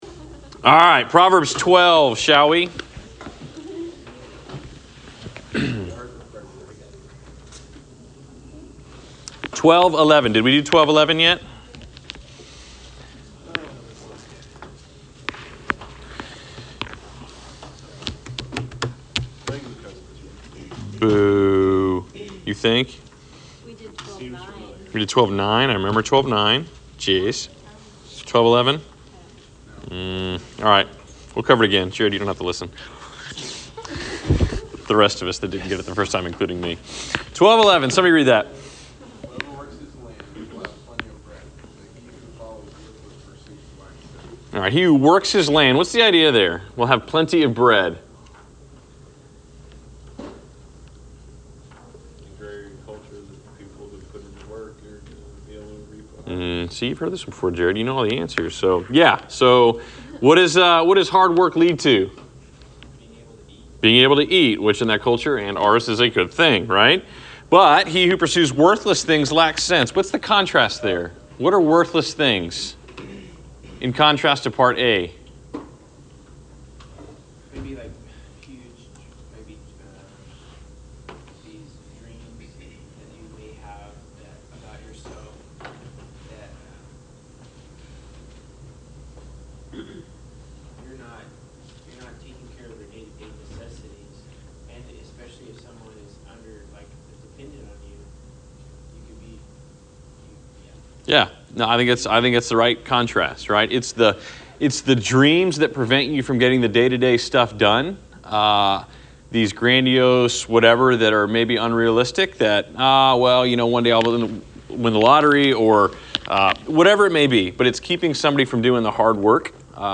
Class Session Audio February 14